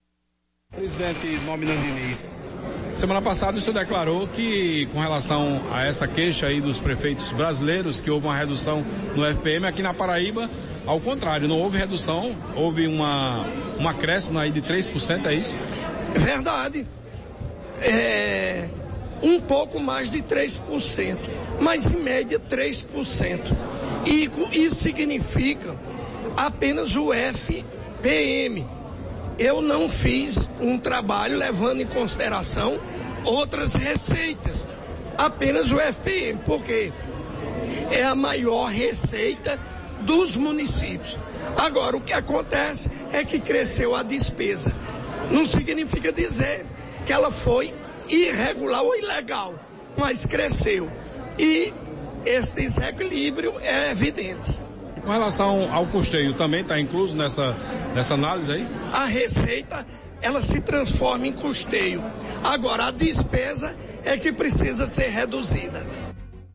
Em entrevista à Rádio Arapuan FM, nesta segunda-feira (9), Nominando afirmou que na Paraíba, os municípios receberam cerca de 3% a mais no repasse do Fundo, entretanto, segundo ele, o que pode-se afirmar é que o desequilibrio financeiro tem como base o aumento de despesas.